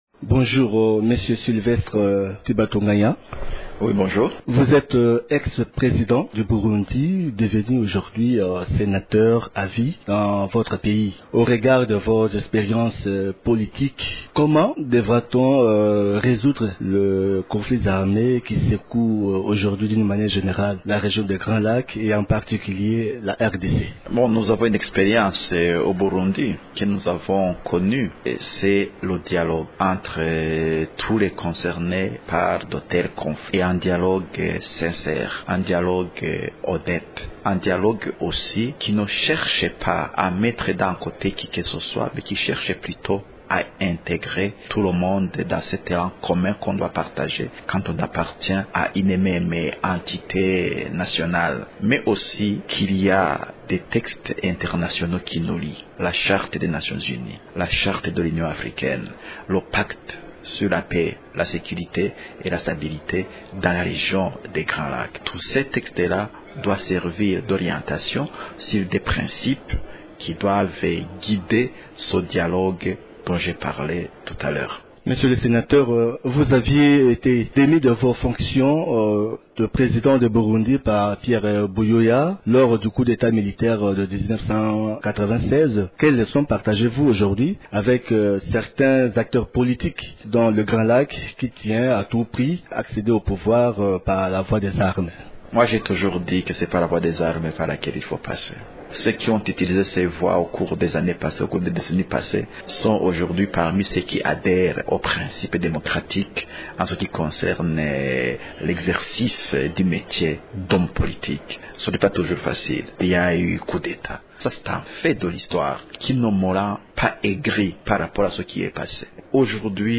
L’invité de la Radio Okapi de ce matin est l’ex-président du Burundi, Sylvestre Ntibantunganya, qui a pris part aux assises de la troisième session de l’Assemblée plénière des Parlements des pays de la région des Grands Lacs, tenues du 23 au 25 janvier à Kinshasa.